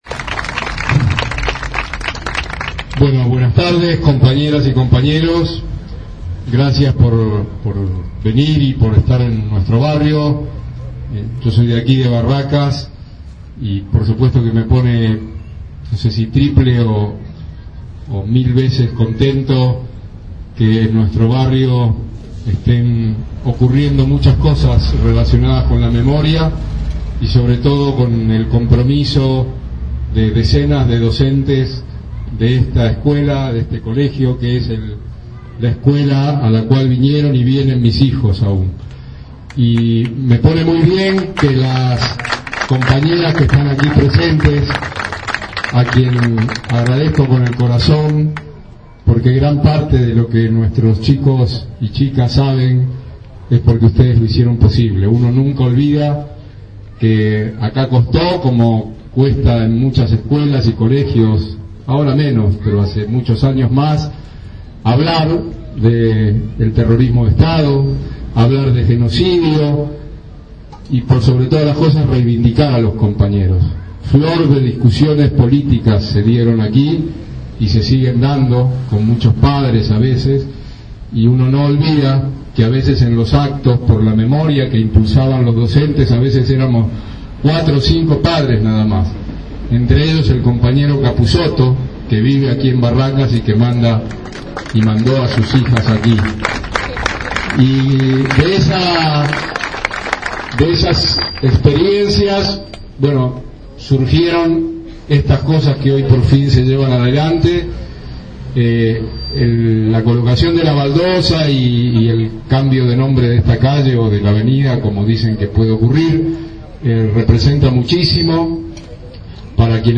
En la esquina del pasaje Coronel Rico y avenida Suárez se descubrió una placa en su memoria.
Aquí algunos de los discursos que se escucharon.